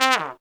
Index of /90_sSampleCDs/Zero-G - Phantom Horns/TRUMPET FX 3